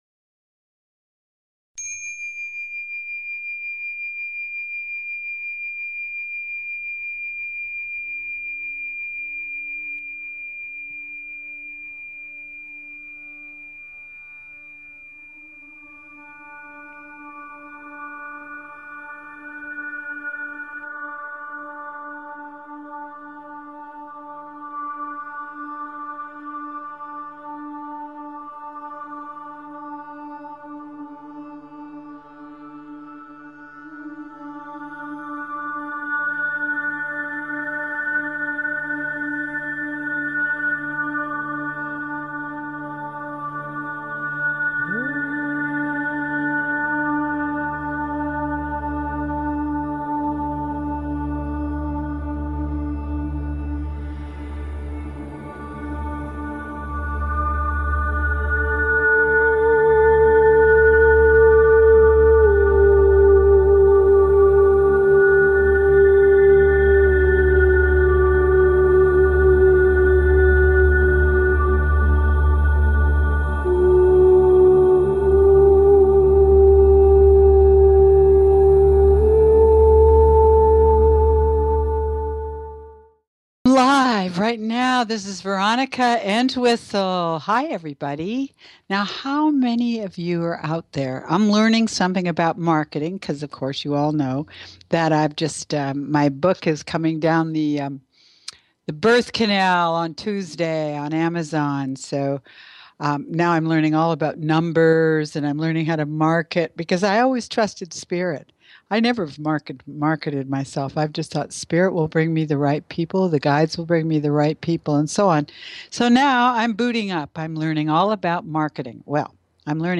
Radiance By Design is specifically tailored to the energies of each week and your calls dictate our on air discussions. Together we explore multi-dimensional realities, healing through energy structures, chakra tuning, the complexities